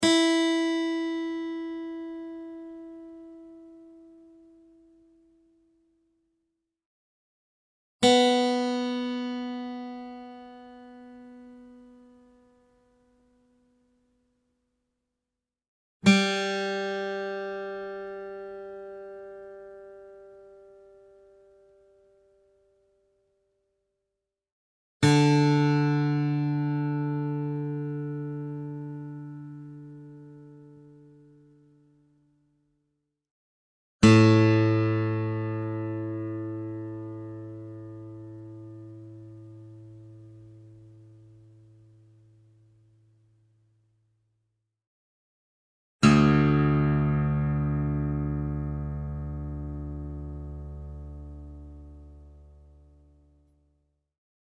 Real acoustic guitar sounds in Drop D Tuning
Guitar Tuning Sounds